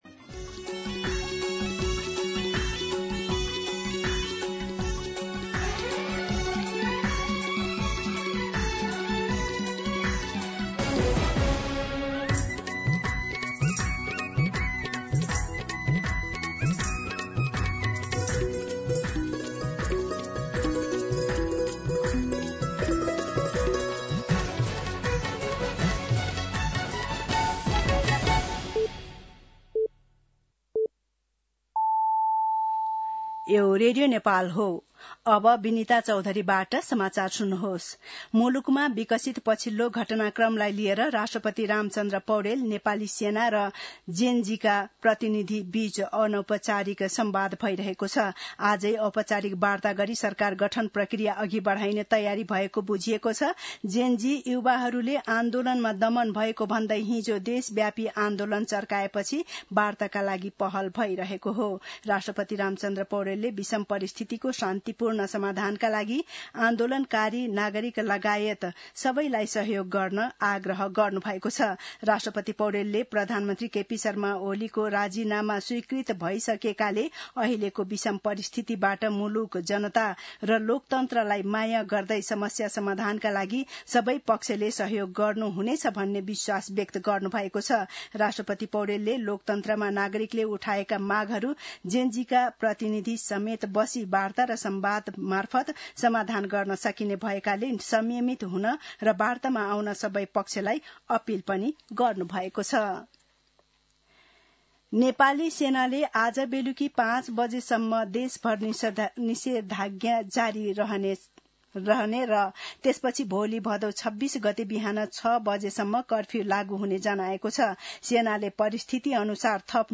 मध्यान्ह १२ बजेको नेपाली समाचार : २५ भदौ , २०८२